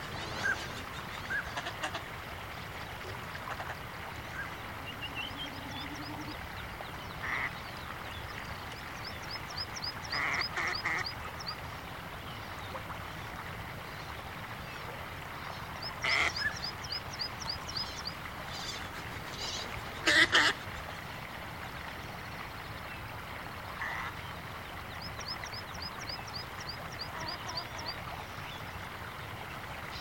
canard-pilet.mp3